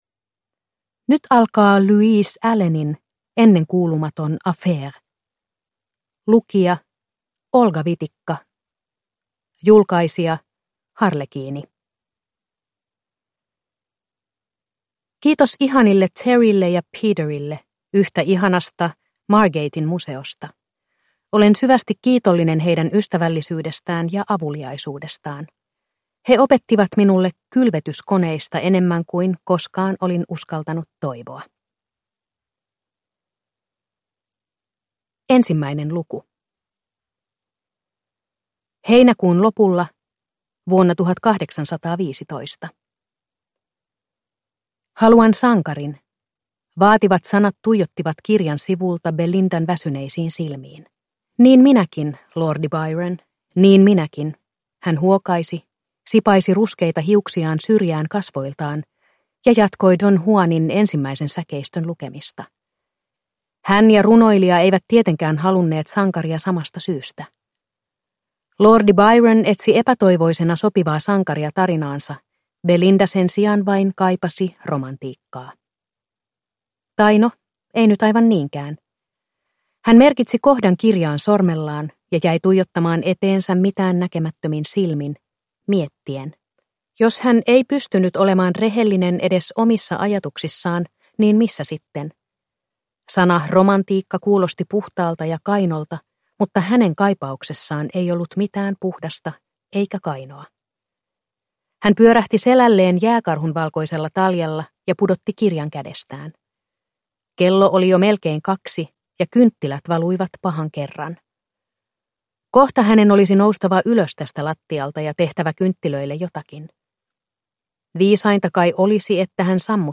Ennenkuulumaton affaire (ljudbok) av Louise Allen